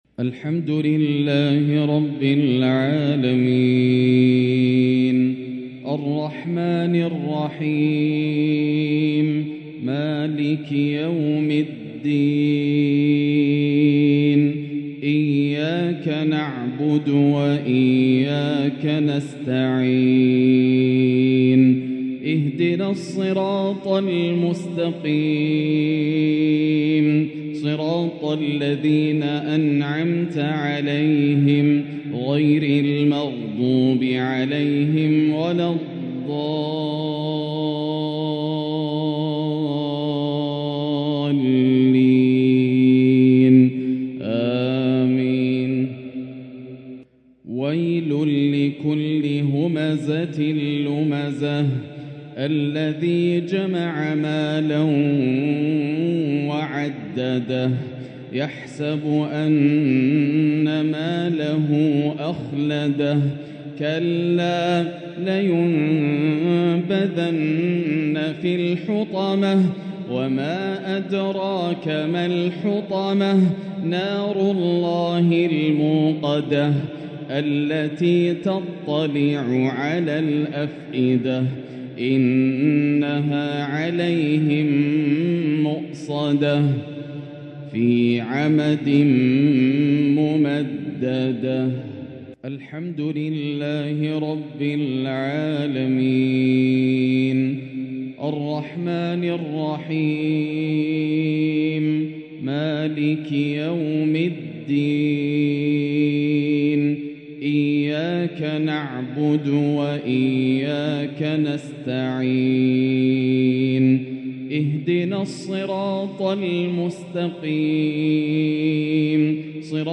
تلاوة لسورتي الهمزة والمسد | مغرب الخميس 2-1-1445هـ > عام 1445 > الفروض - تلاوات ياسر الدوسري